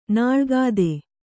♪ nāḷgāde